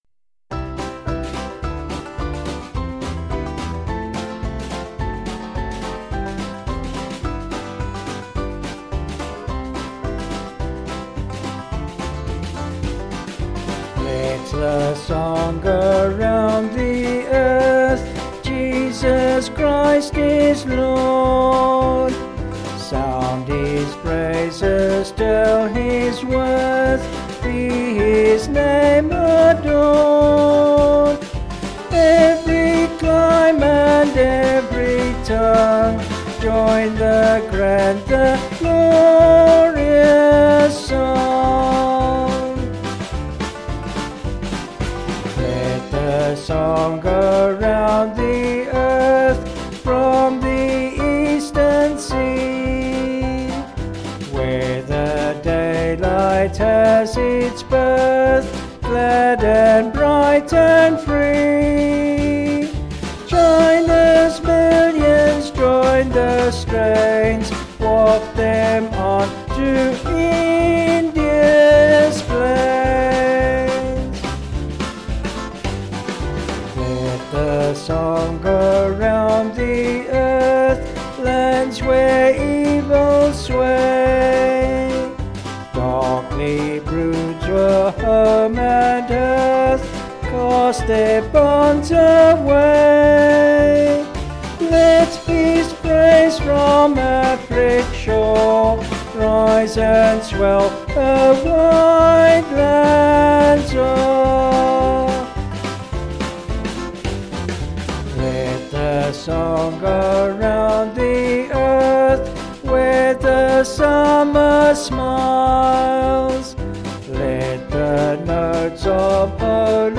Vocals and Organ   264kb Sung Lyrics 2.4mb